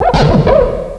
pokeemerald / sound / direct_sound_samples / cries / scrafty.aif
-Replaced the Gen. 1 to 3 cries with BW2 rips.